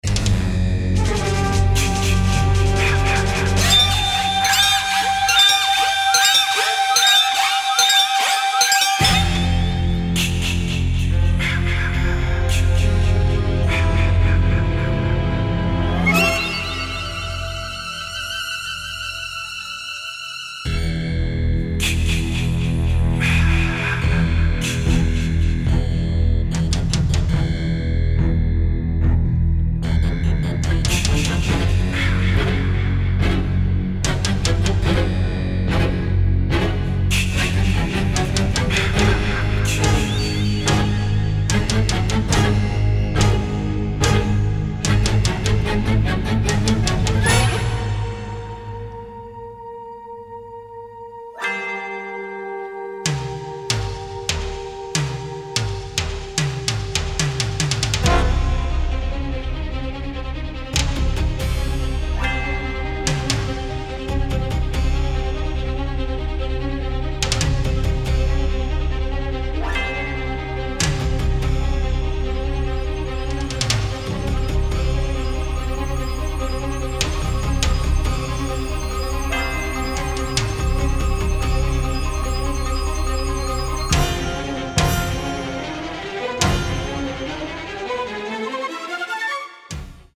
stereo presentation
original motion picture score